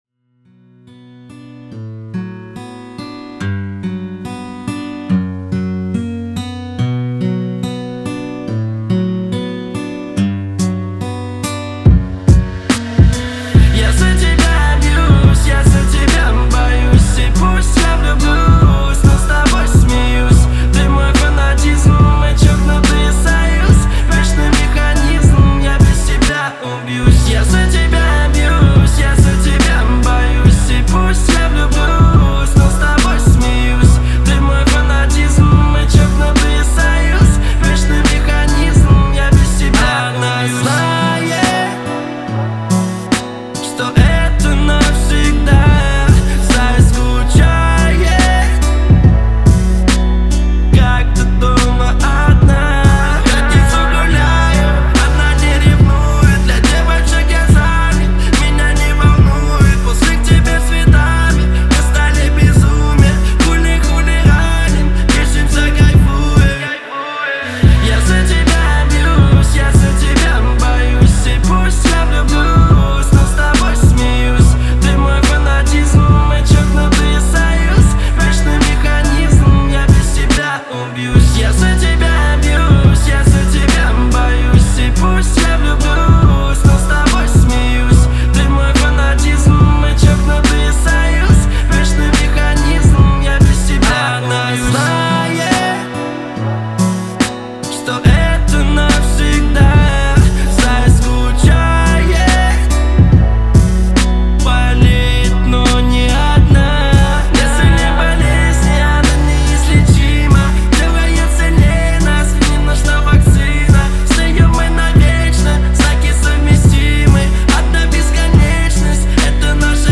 это яркий пример современного русского хип-хопа